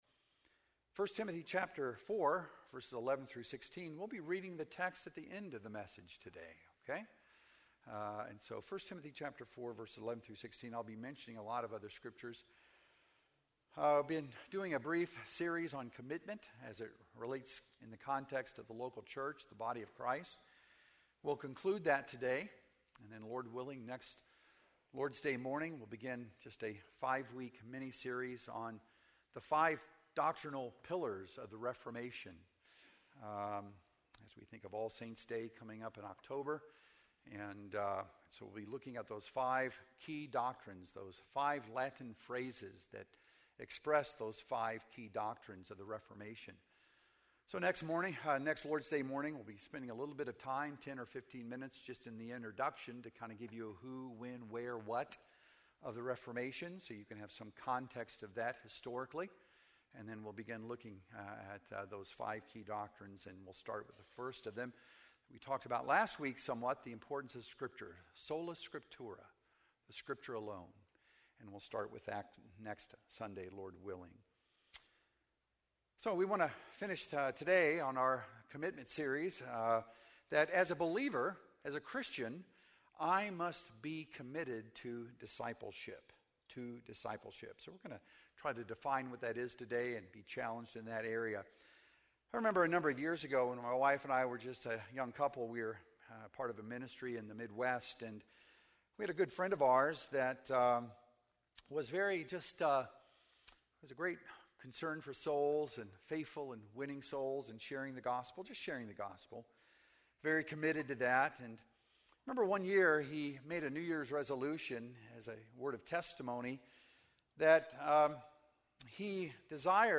Sermons - Emmanuel Baptist Church
Sunday Morning Worship